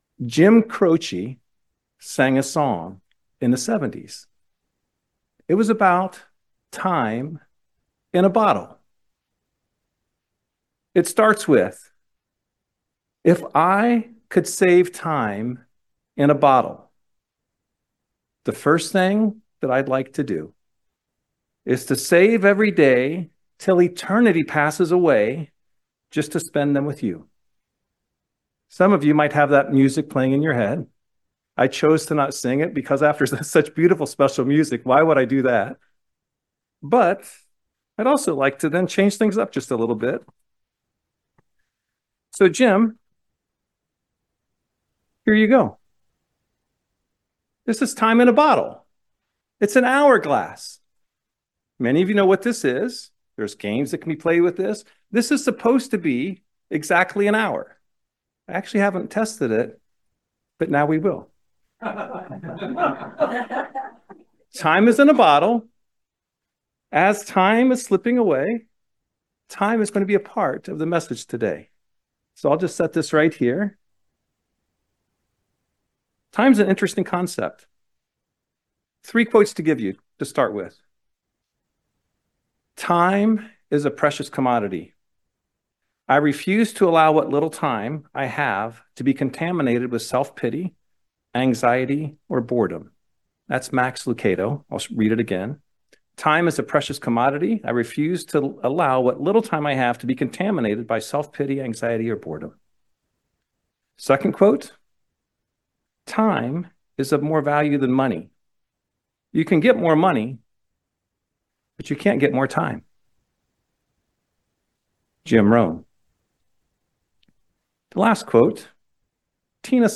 Sermons
Given in Lexington, KY